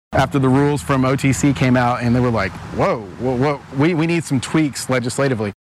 CLICK HERE to listen to commentary from State Representative Jacob Rosencrans.